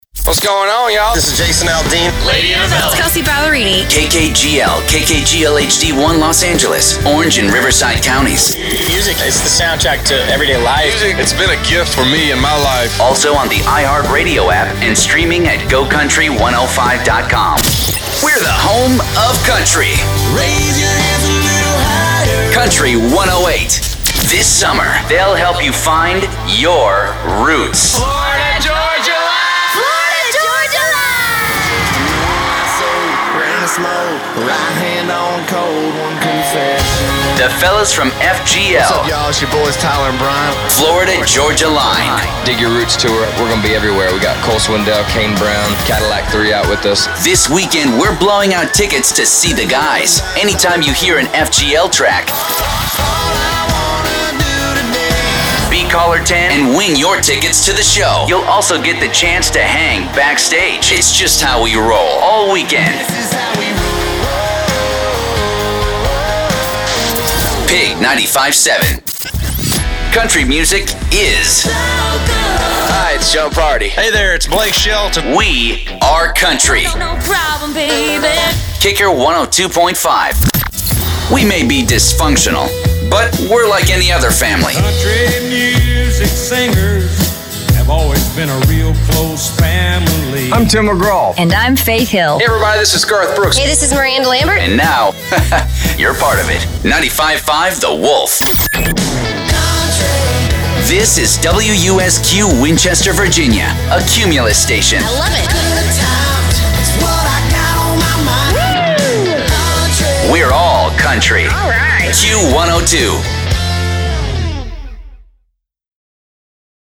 Imaging Demo
Country Voice Over